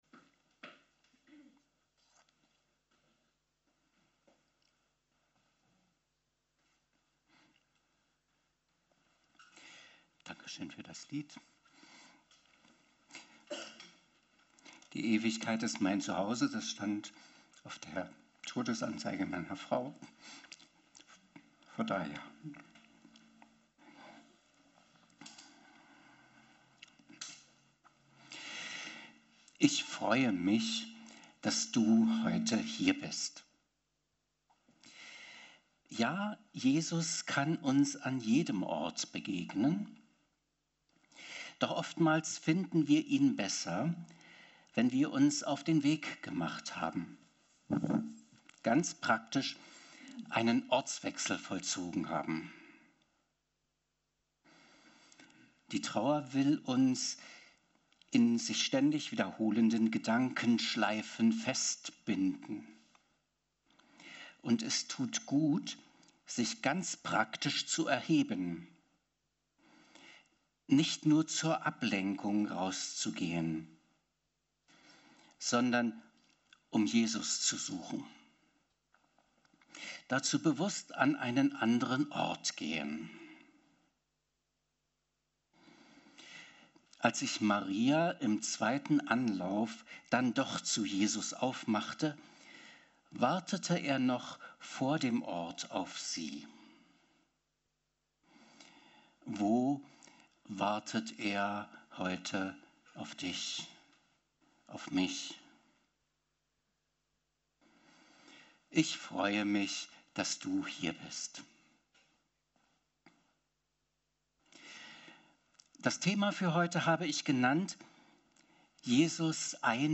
Evangelisch-Freikirchliche Gemeinde Kelkheim - Predigten anhören